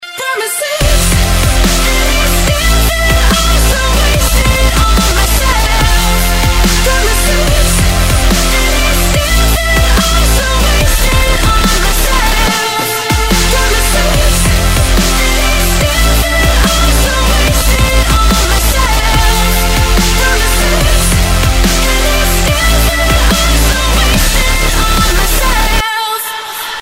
Дабстеп рингтоны